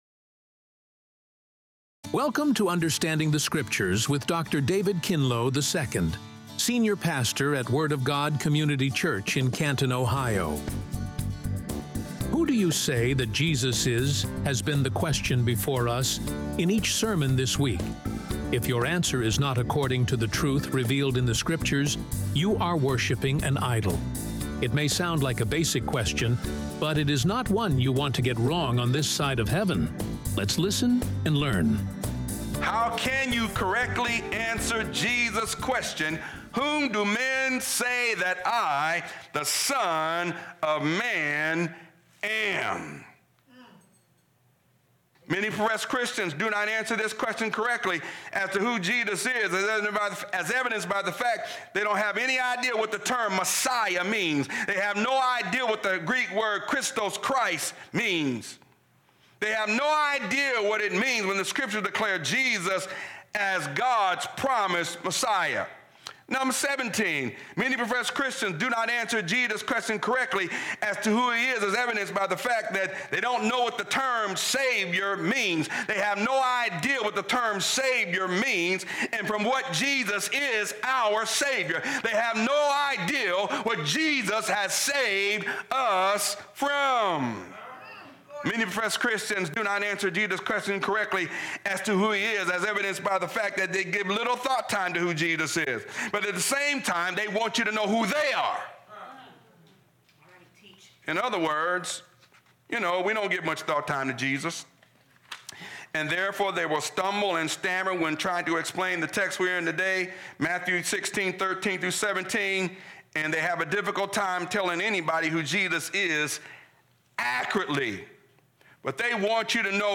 (#2) RADIO SERMON Who Do Men Say Jesus Is?